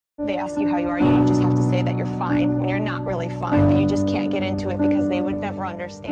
They-ask-you-how-you-are-and-you-just-have-to-say-that-youre-fine-Sound-Effect.mp3